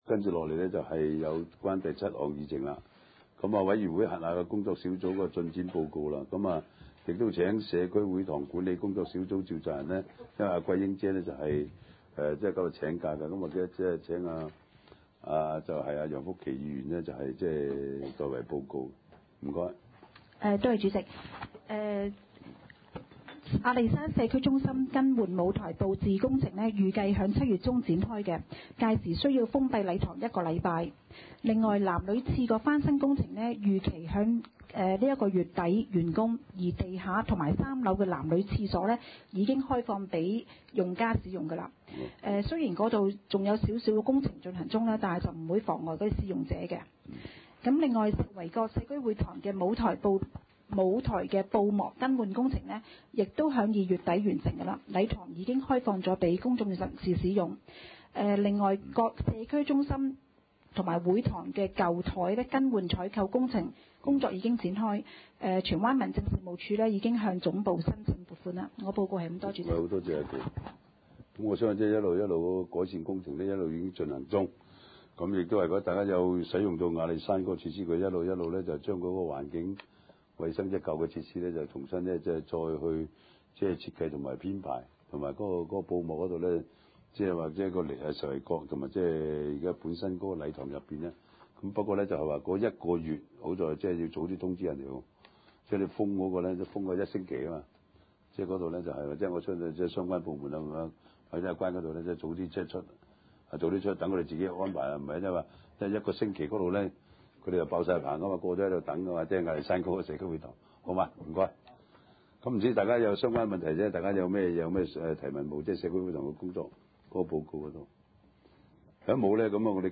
地區設施管理委員會第二十次會議
荃灣民政事務處會議廳